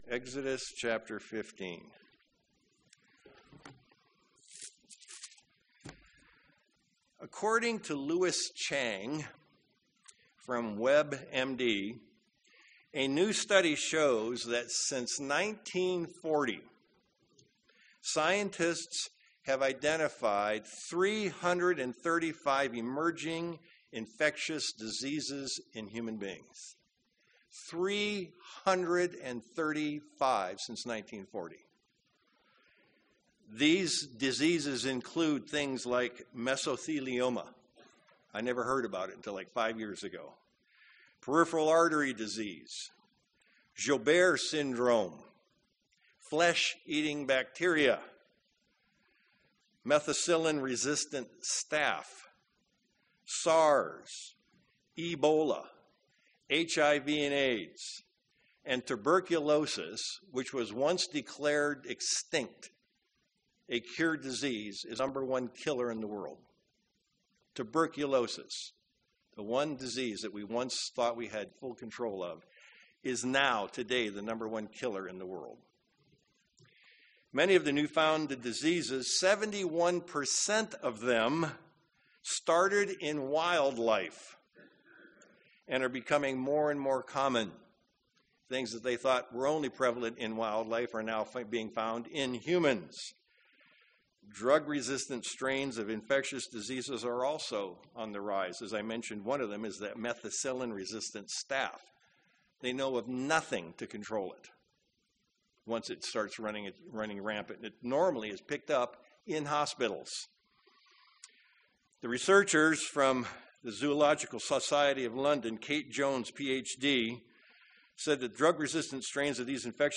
Given in Sacramento, CA
UCG Sermon Studying the bible?